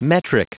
Prononciation du mot metric en anglais (fichier audio)
Prononciation du mot : metric